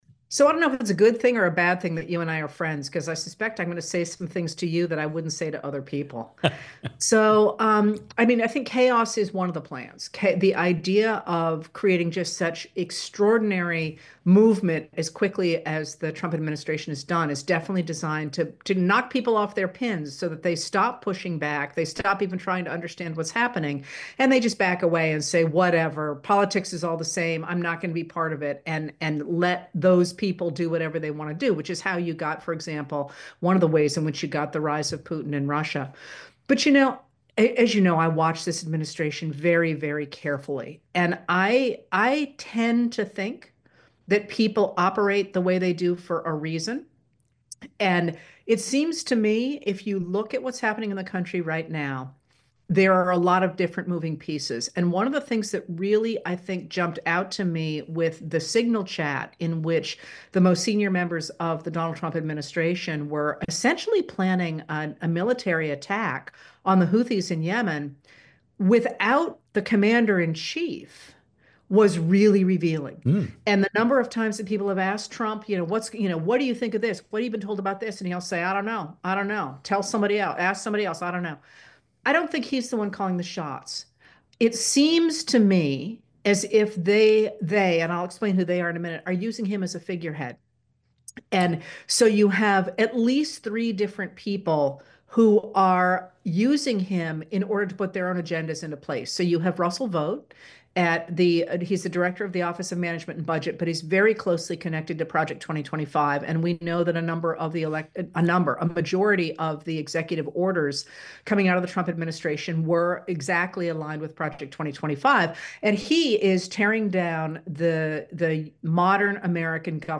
In a clip from our upcoming conversation, Heather Cox Richardson talks about Trump Administration “plans” and the recent Signalgate debacle.